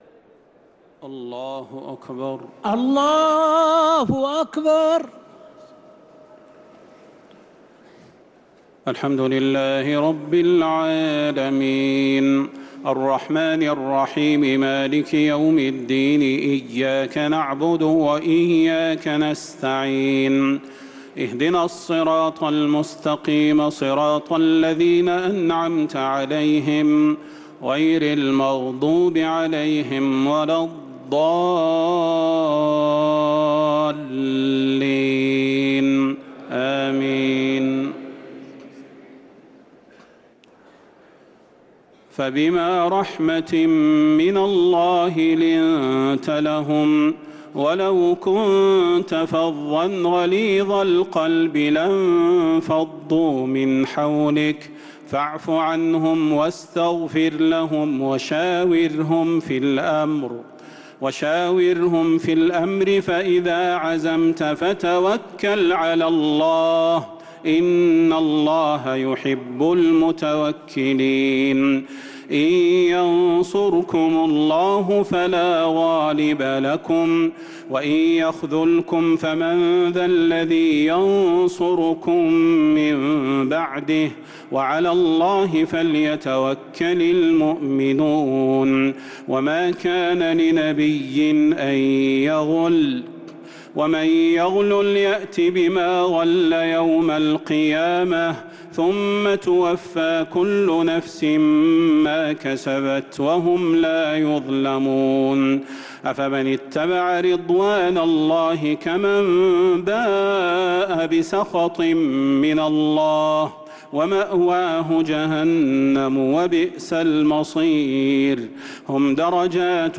تراويح ليلة 5 رمضان 1446هـ من سورة آل عمران (159-200) | Taraweeh 5th night Ramadan 1446H Surah Aal-i-Imraan > تراويح الحرم النبوي عام 1446 🕌 > التراويح - تلاوات الحرمين